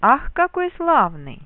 Мелодические схемы восклицательных предложений (первого типа):
1. на очень высоком тоне (сравнительно с остальными);
2. с очень сильной интенсивностью;